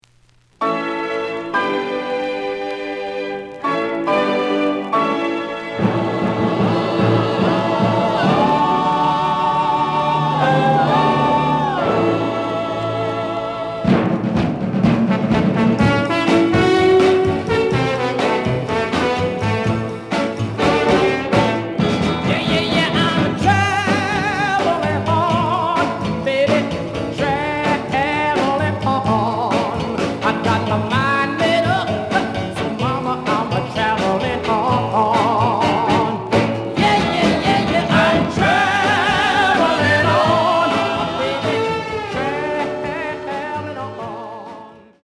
The listen sample is recorded from the actual item.
●Format: 7 inch
●Genre: Soul, 60's Soul